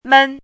“闷”读音
mēn
闷字注音：ㄇㄣˋ/ㄇㄣ
国际音标：mən˥˧;/mən˥